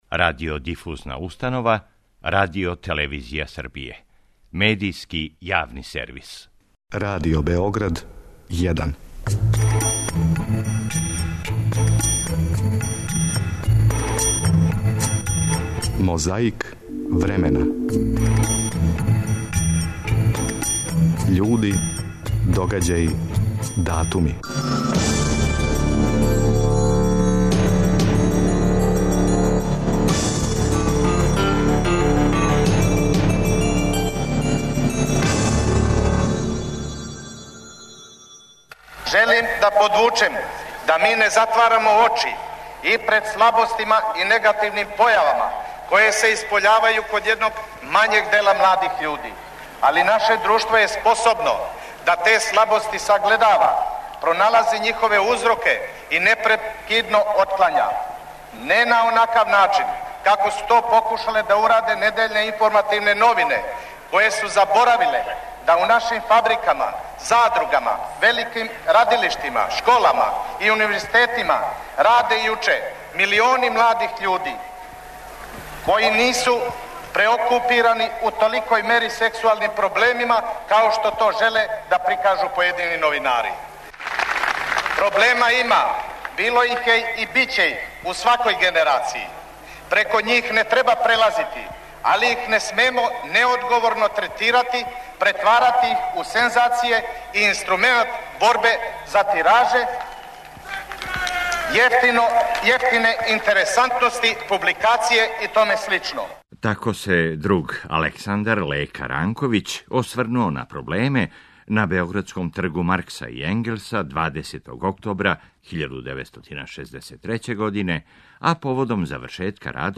Како се друг Александар Лека Ранковић осврнуо на проблеме на београдском Тргу Маркса и Енгелса 20. октобра 1963. године, а поводом завршетка радова на ауто путу Братство – јединство, чућете на почетку овонедељне борбе против пилећег памћења.